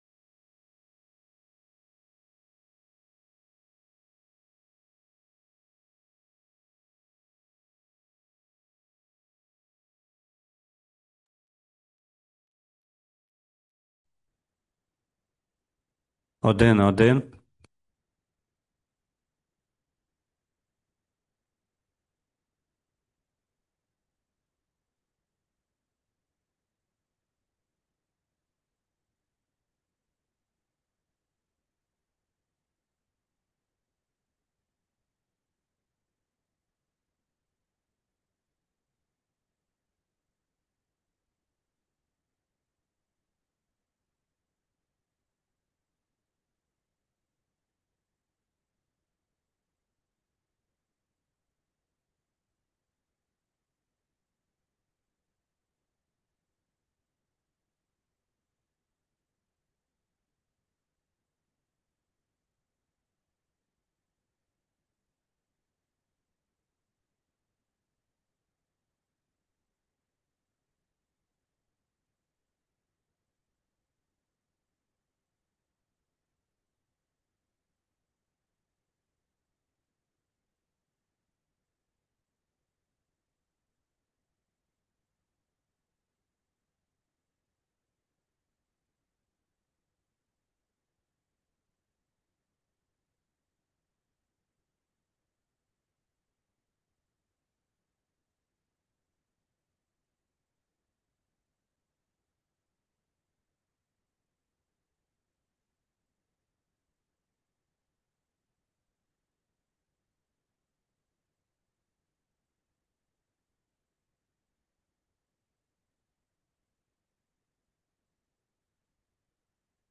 Засідання Комітету від 19 січня 2026 року